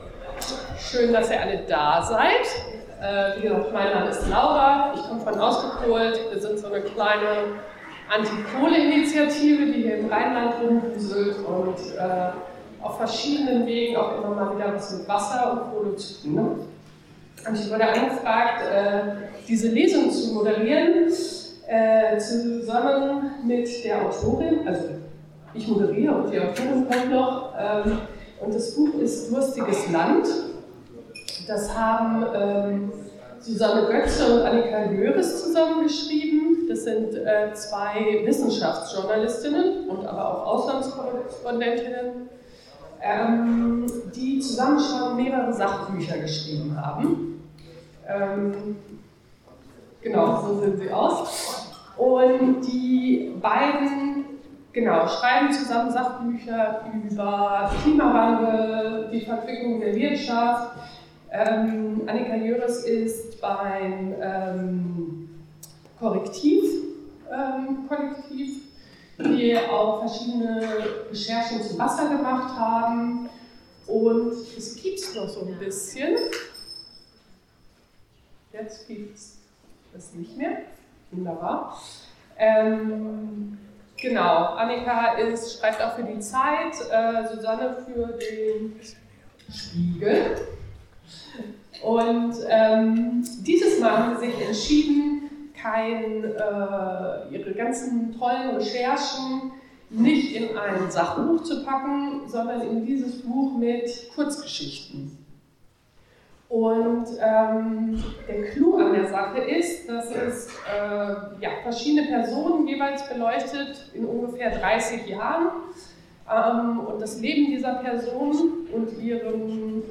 Wasserkonferenz: Lesung “Durstiges Land“ wie wir leben wenn das Wasser knapp wird – radio nordpol
Eine Lesung und Diskussionsrunde über die Recherche und das Buch „Durstiges Land“ – ein Blick in unsere Zukunft.